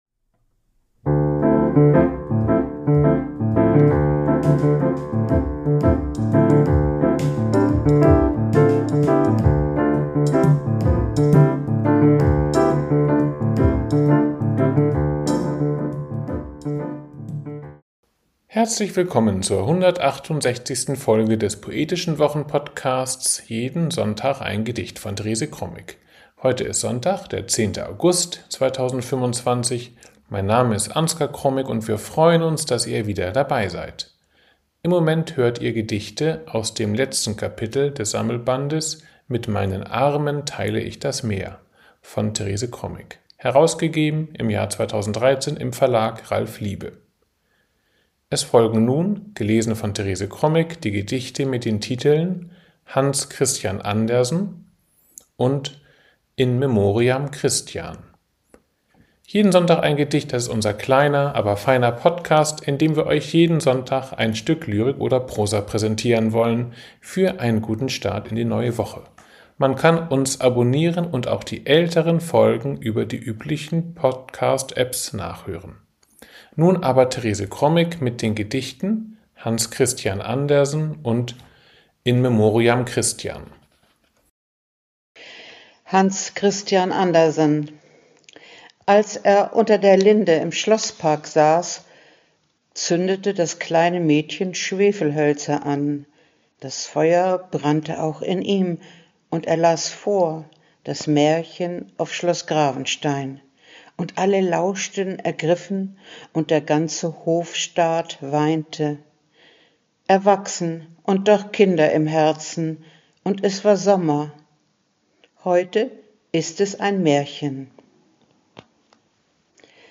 Im Moment hört ihr Gedichte aus dem letzten Kapitel des